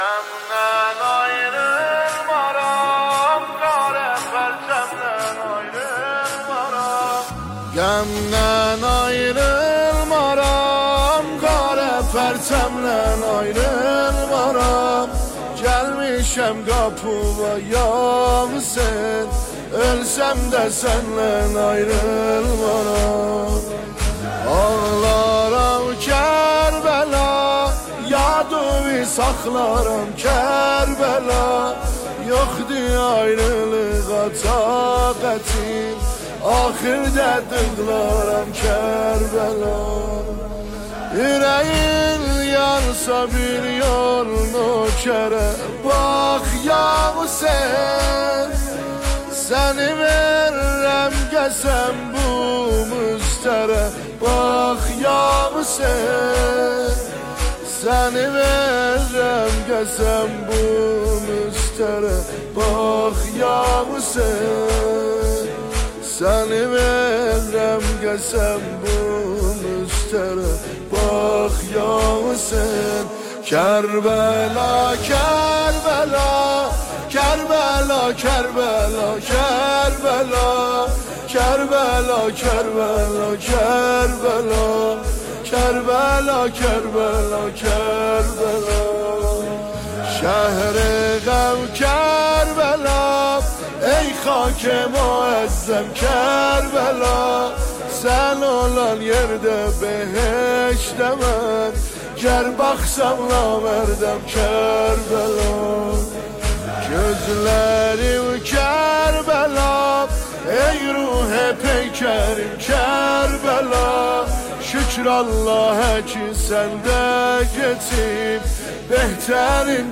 نماهنگ آذری دلنشین